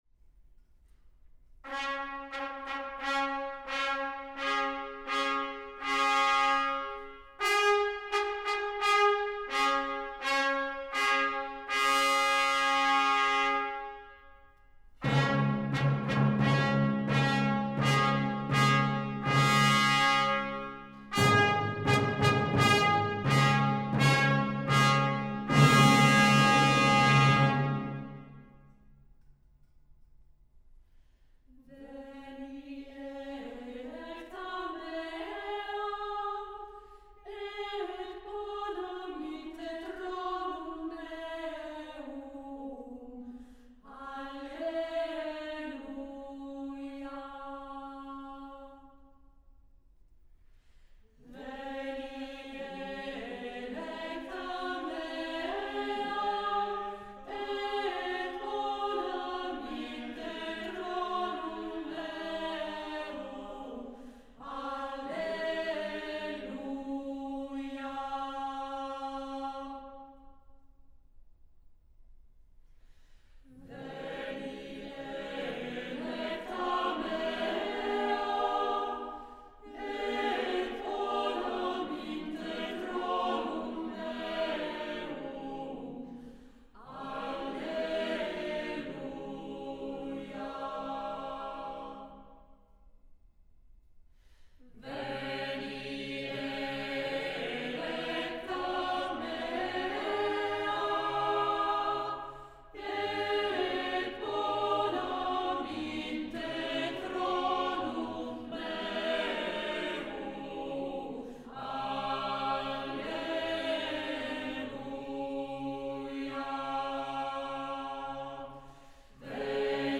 È stata inoltre utilizzata come base di una serie di elaborazioni in polifonia semplice, di voci sole o in concerto con strumenti, che realizzano una plausibile successione performativa rapportata alla raffigurazione. Sono stati così eseguiti una “Diafonia” a due voci, un “Falso bordone” a 3 voci e un Mottetto concertato in canto figurato pure a 3 voci.
L’insieme costituisce il “Concerto angelico per il Ballo dell’Incoronazione” esemplato sulla scena raffigurata dal Ghirlandaio e realizzato specialmente in aderenza alle voci e agli strumenti ivi rappresentati, nonché alla evidente destinazione della performance come accompagnamento alla danza, la quale risulta richiamata nell’esecuzione sia da ritmo binario (per la Pavana) sia da ritmo ternario (per il saltarello).
Il brano è stato registrato nell’Auditorio “Stelio Moro” di Lugano della RSI RADIOTELEVISIONE SVIZZERA. Coro e Ensemble di strumenti antichi della RSI.
Direttore: Diego Fasolis